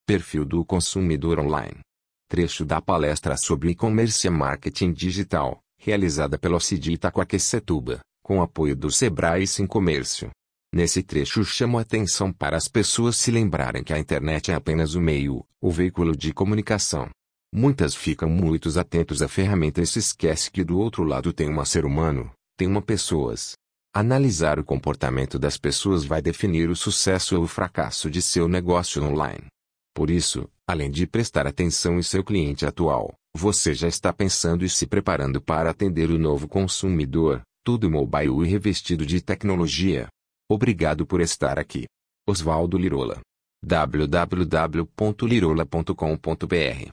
Trecho da Palestra sobre E-commerce e Marketing Digital, realizada pelo ACIDI Itaquaquecetuba, com apoio do SEBRAE e SINCOMERCIO.